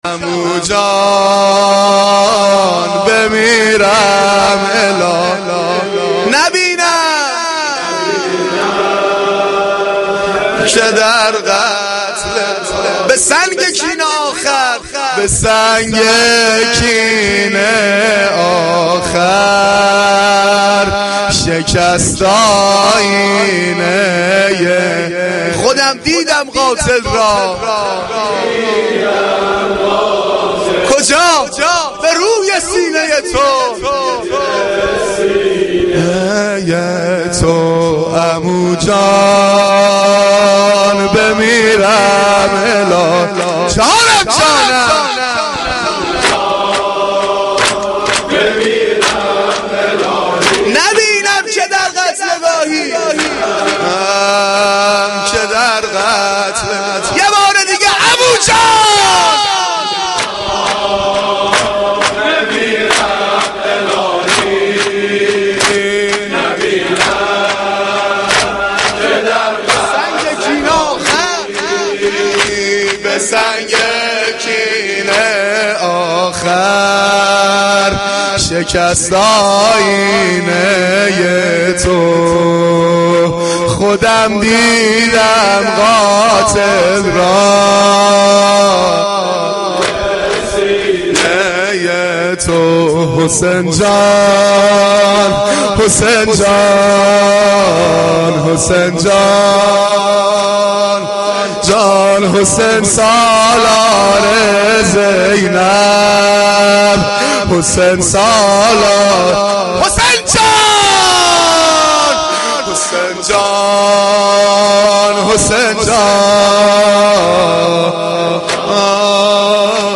مداحی
Shab-5-Moharam-4.mp3